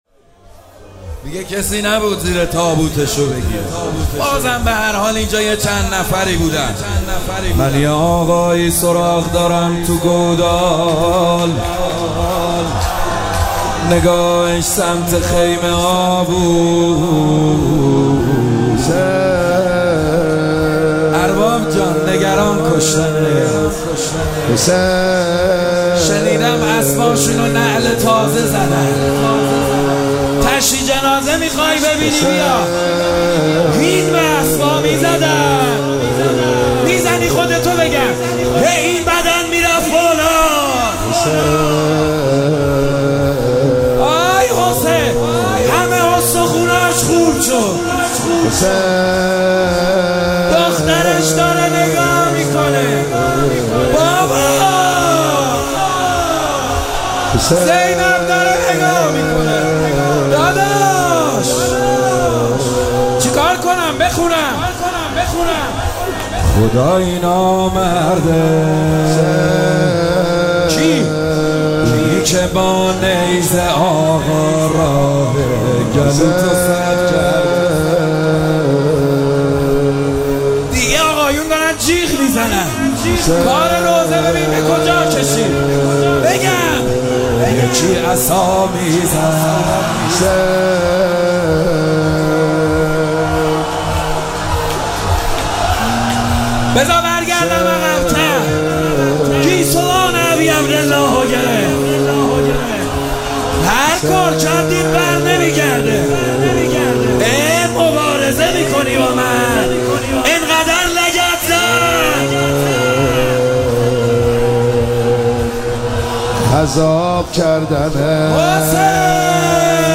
شب چهارم فاطمیه دوم صوتی -روضه - محمد حسین حدادیان